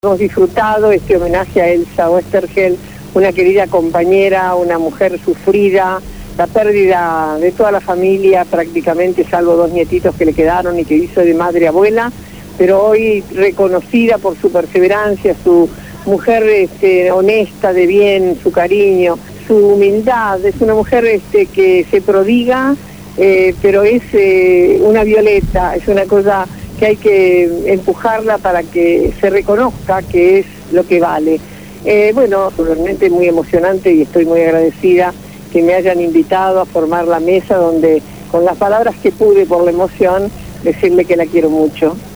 Elsa Sánchez de Oesterheld fue homenajeada en el Salón San Martín de la Legislatura de la Ciudad de Buenos Aires.